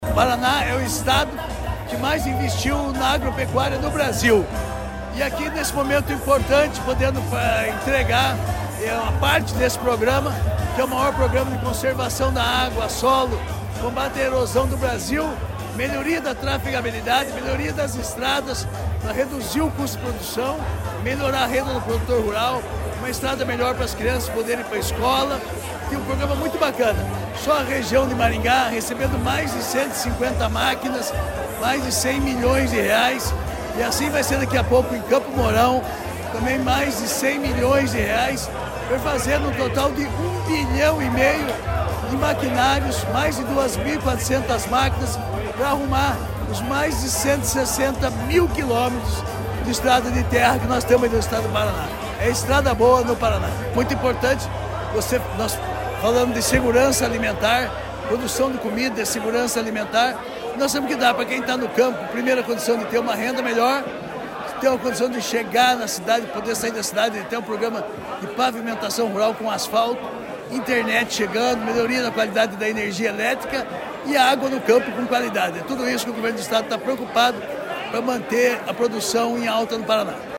Sonora do secretário da Agricultura e do Abastecimento, Márcio Nunes, sobre o investimento em máquinas para melhorar estradas rurais